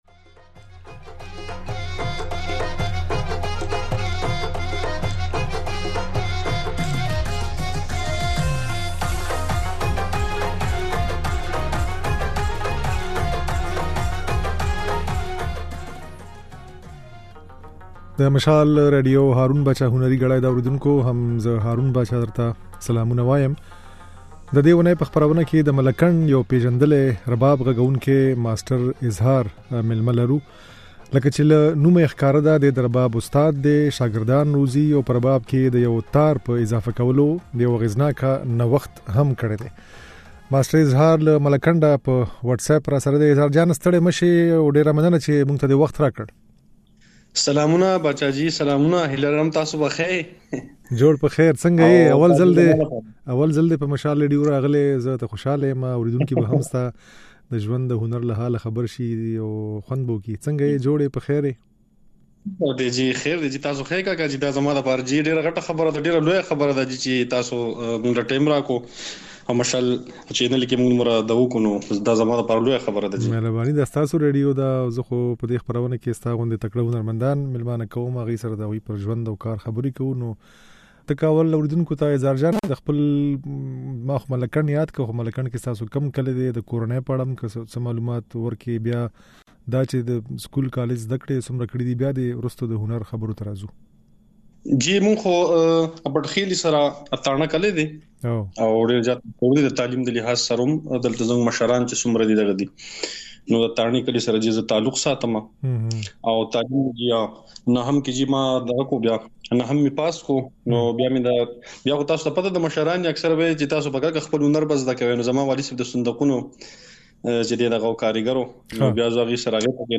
ځينې نغمې يې په خپرونه کې اورېدای شئ.